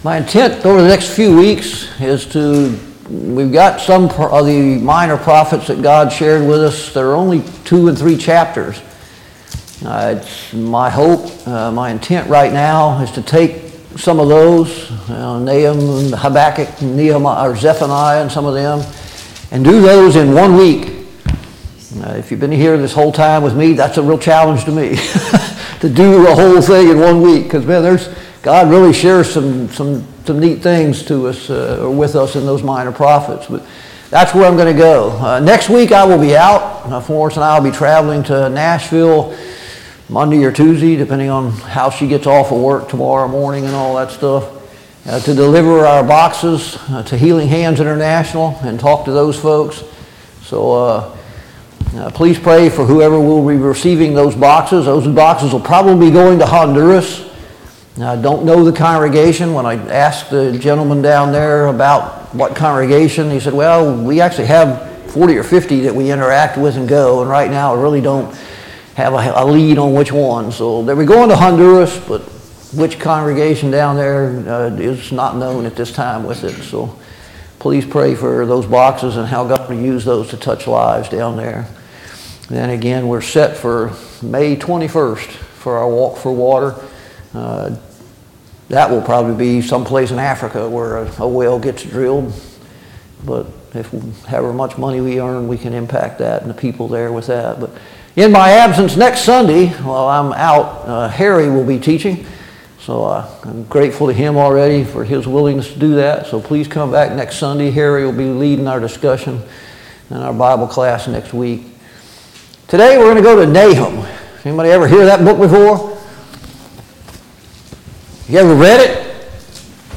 Nahum Service Type: Sunday Morning Bible Class « Are you thankful for your blessings?